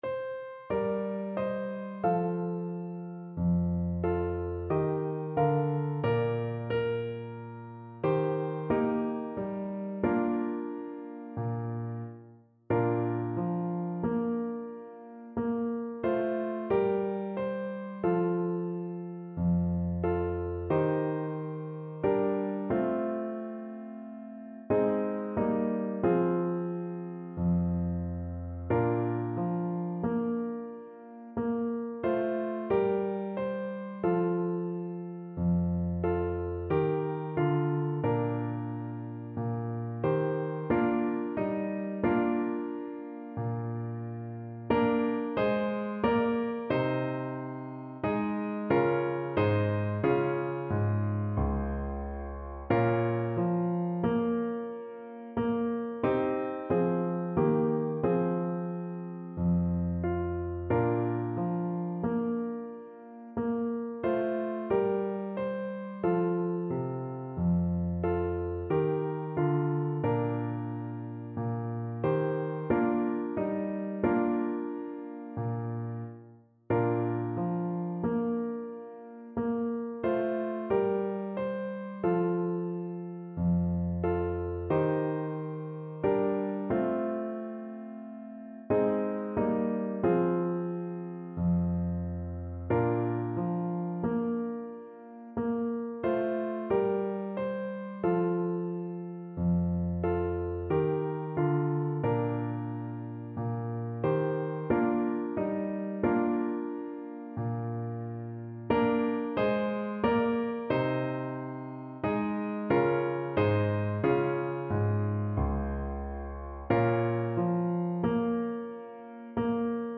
Classical Trad. The Wexford Carol Clarinet version
Christmas Christmas Clarinet Sheet Music The Wexford Carol
Clarinet
Bb major (Sounding Pitch) C major (Clarinet in Bb) (View more Bb major Music for Clarinet )
Slow, expressive =c.60
3/4 (View more 3/4 Music)
Classical (View more Classical Clarinet Music)